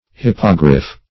hippogriff - definition of hippogriff - synonyms, pronunciation, spelling from Free Dictionary
Hippogriff \Hip"po*griff\, n. [F. hippogriffe; cf. It.